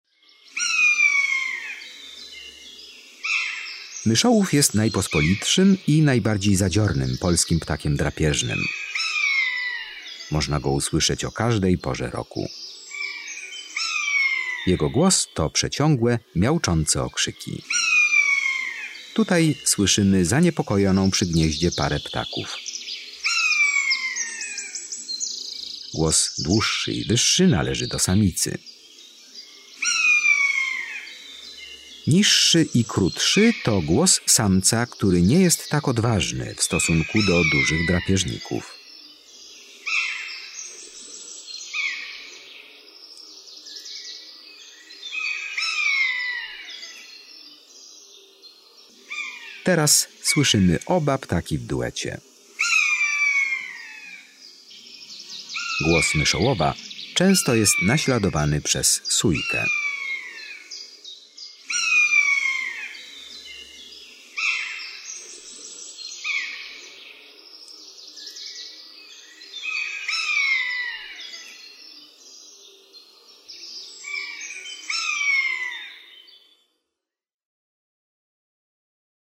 13 MYSZOŁÓW.mp3